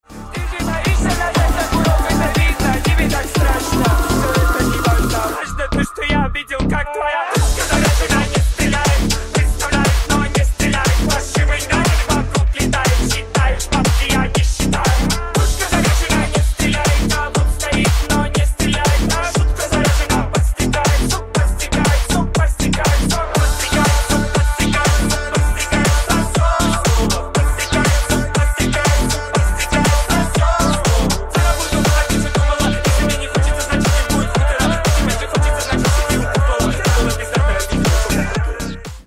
Громкие Рингтоны С Басами
Танцевальные Рингтоны